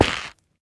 Minecraft Version Minecraft Version snapshot Latest Release | Latest Snapshot snapshot / assets / minecraft / sounds / block / stem / step6.ogg Compare With Compare With Latest Release | Latest Snapshot
step6.ogg